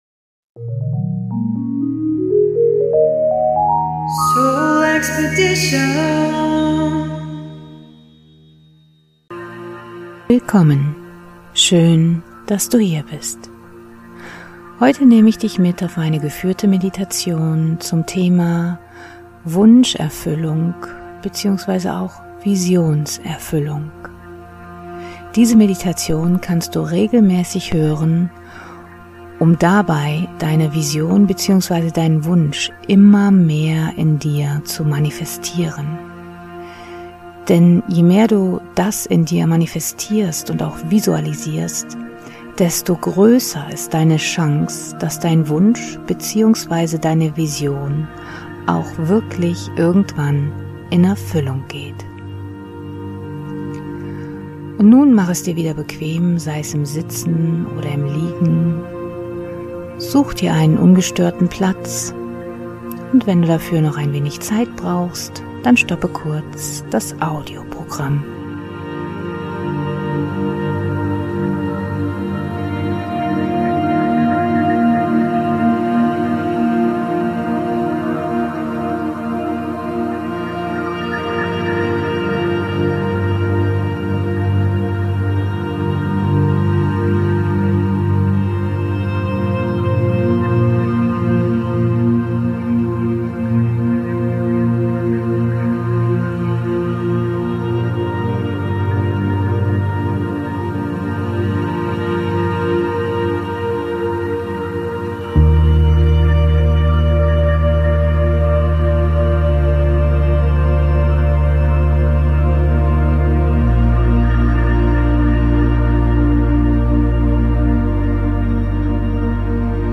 Meditation/Hypnose Wunsch- u. Visionserfüllung ~ SoulExpedition Podcast